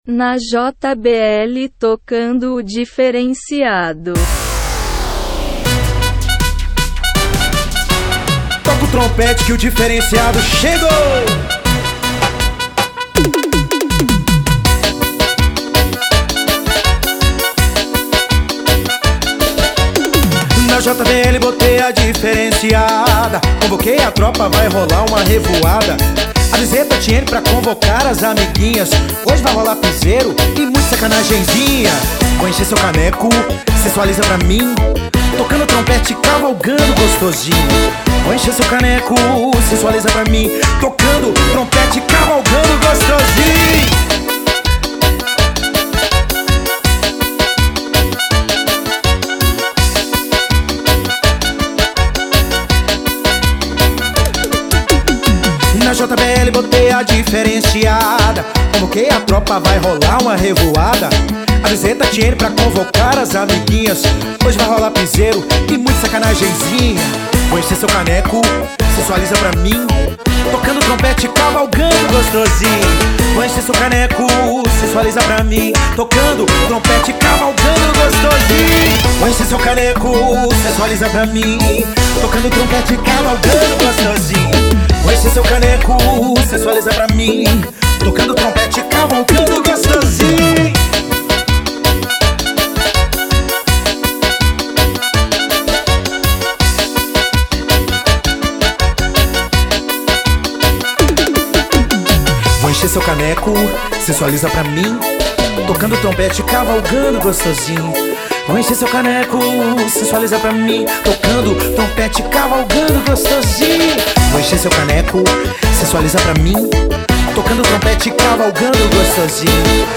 EstiloPiseiro